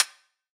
UHH_ElectroHatC_Hit-18.wav